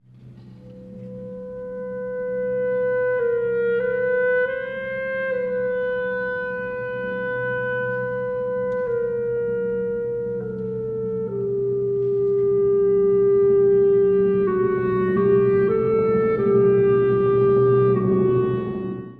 ※↑古い音源なので聴きづらいかもしれません！（以下同様）
深い森から現れるようなクラリネットの序奏で始まります。
やがてバイオリンが刻みを導き、視界が一気に開けるかのように主部が始まります。
中間部では詩的なハープがシーンをつなぎ、
クライマックスでは金管が高揚を演出。
しかしその直後、ピチカート2発で唐突に幕が下りる——緊張感に満ちた終結です。